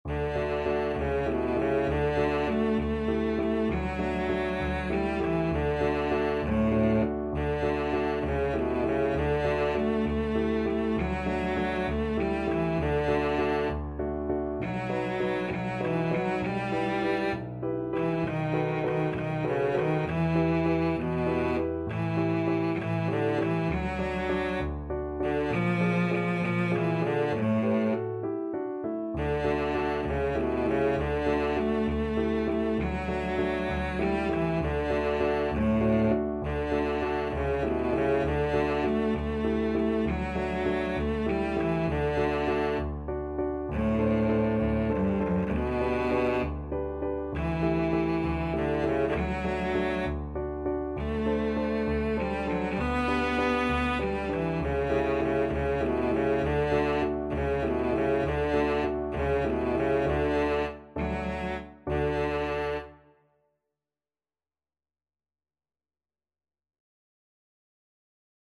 Free Sheet music for Cello
Cello
D major (Sounding Pitch) (View more D major Music for Cello )
. = 66 No. 3 Grazioso
6/8 (View more 6/8 Music)
Classical (View more Classical Cello Music)